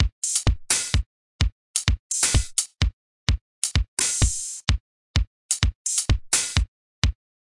基本4/4节拍120bpm " 节拍120bpm08
描述：基本4/4击败120bpm
Tag: 回路 节奏 drumloop 120BPM 节拍 量化 有节奏 常规